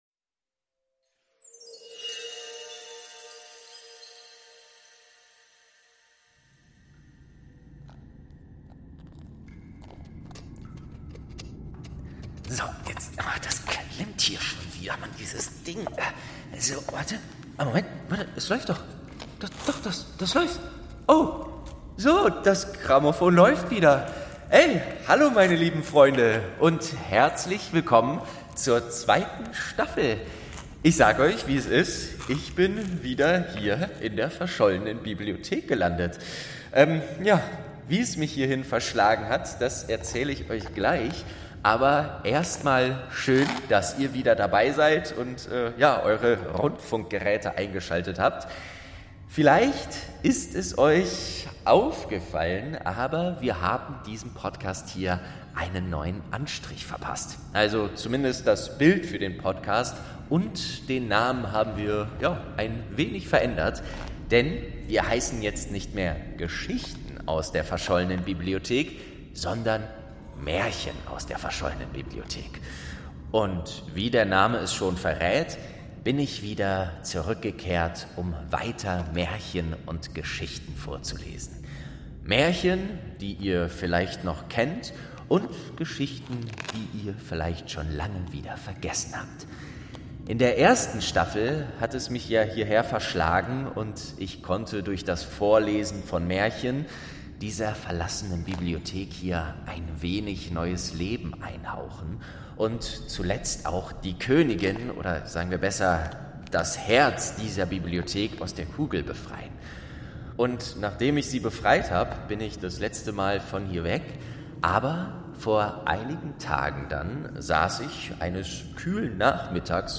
1. Rotkäppchen | Staffel 2 ~ Märchen aus der verschollenen Bibliothek - Ein Hörspiel Podcast